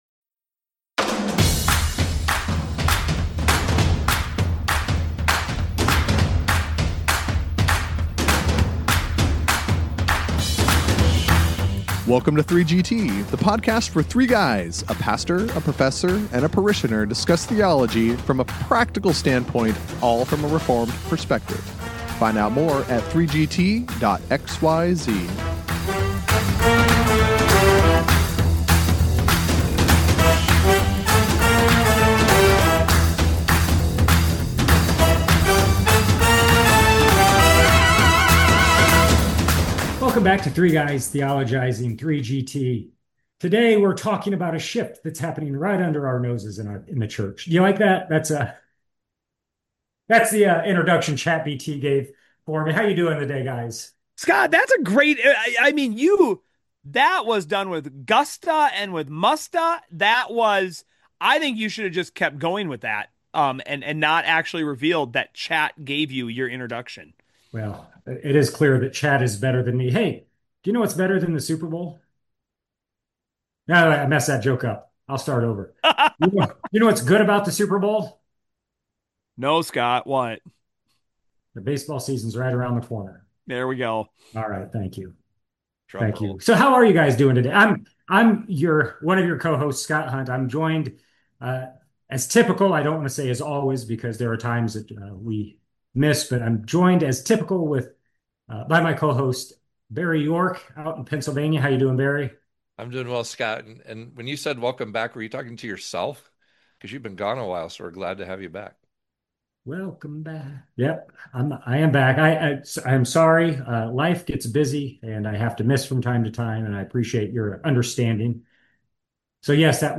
Not one, not two, but three guys theologize on this week’s episode!